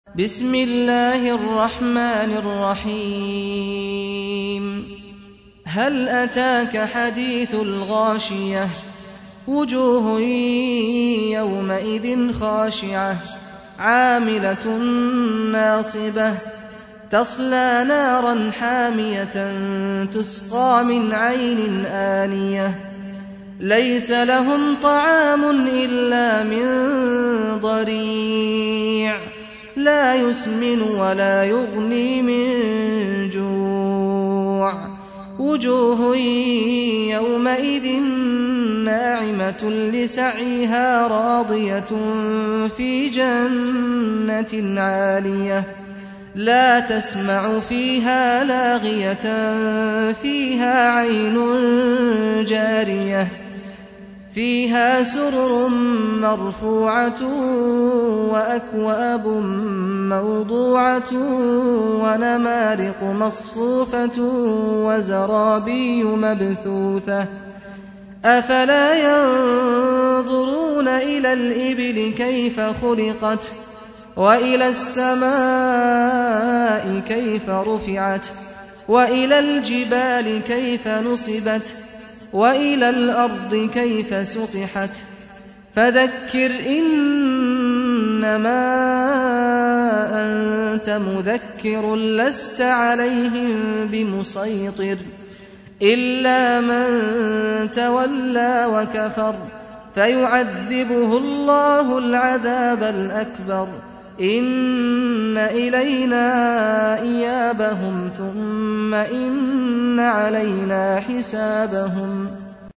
سورة الغاشية | القارئ سعد الغامدي